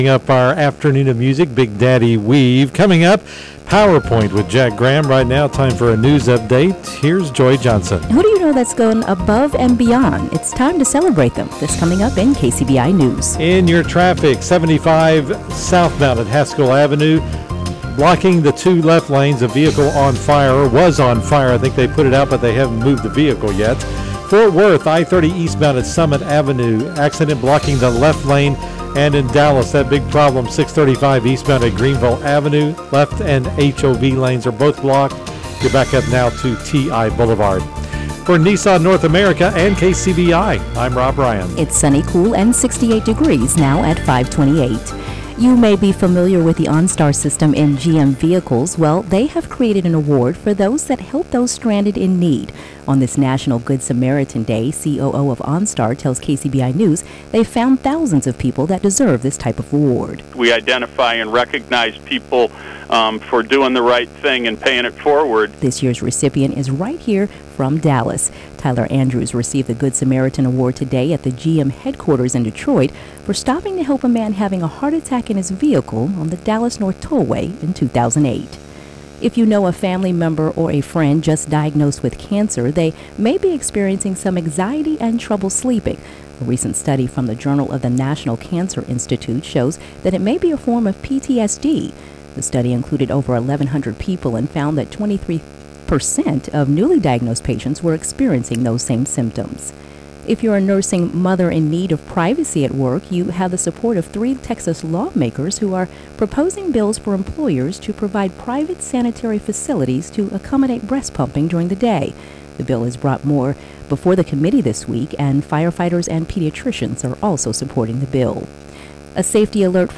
KCBI 90.9 News - Drive Time 5:30 (March 13)